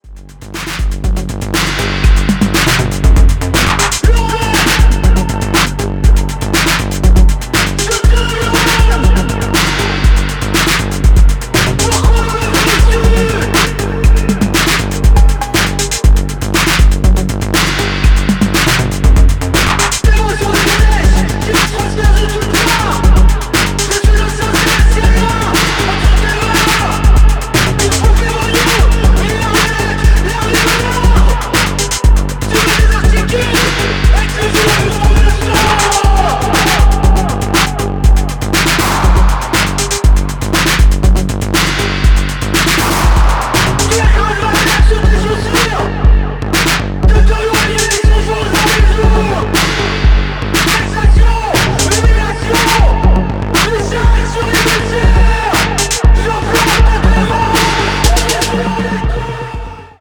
Electro Electronix Acid